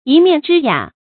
一面之雅 注音： ㄧ ㄇㄧㄢˋ ㄓㄧ ㄧㄚˇ 讀音讀法： 意思解釋： 見「一面之交」。